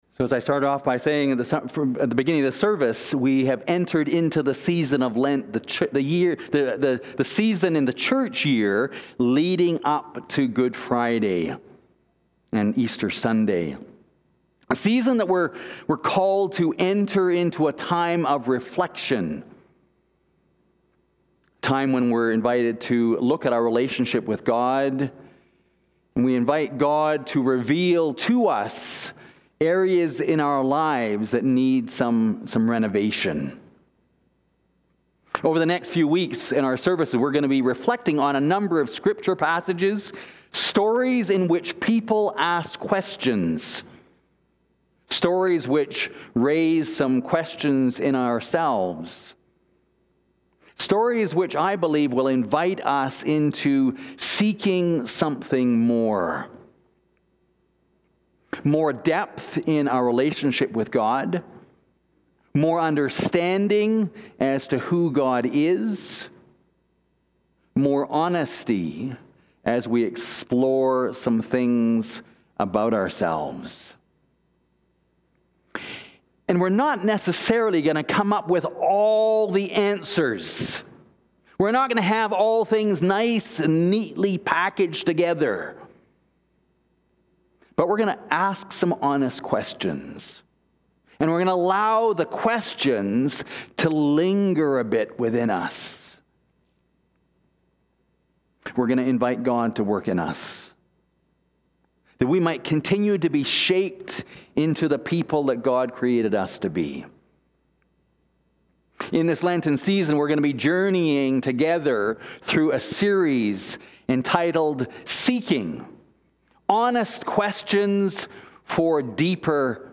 Who Will You Listen To? - Holy Trinity Anglican Church (Calgary)